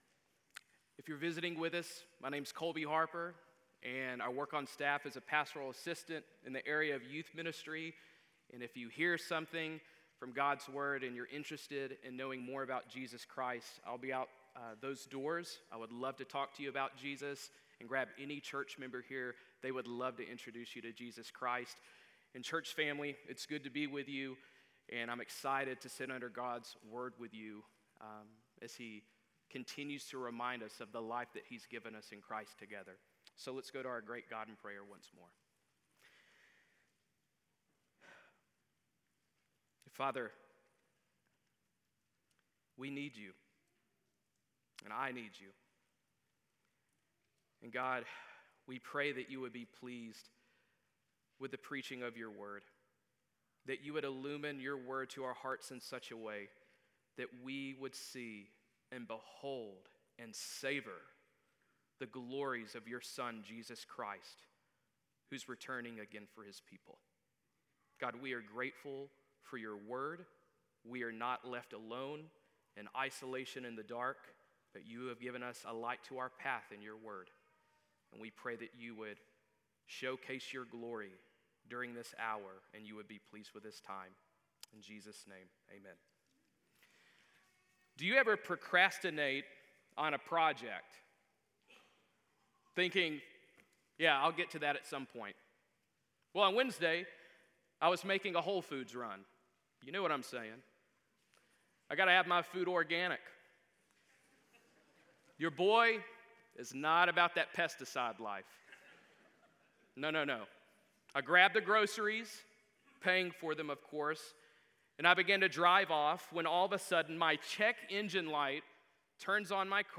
Sermon Audio | University Baptist Church